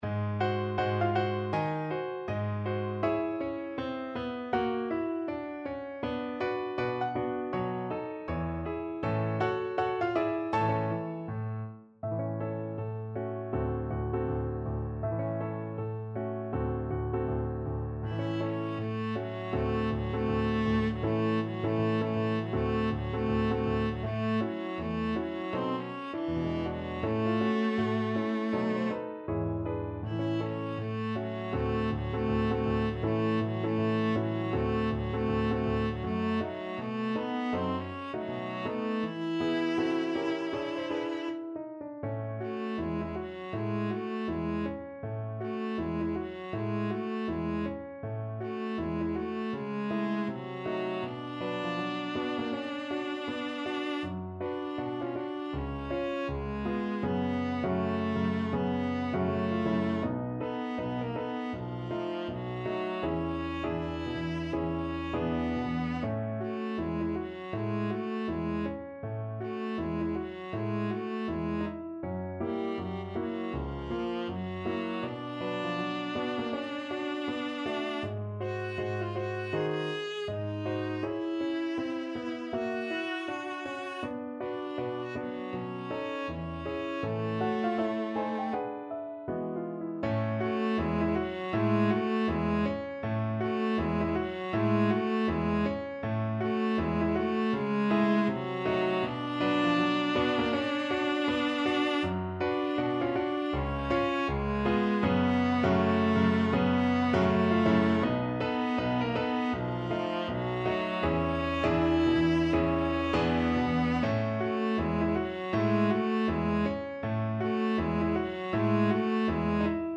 ~ = 160 Moderato
Jazz (View more Jazz Viola Music)
Rock and pop (View more Rock and pop Viola Music)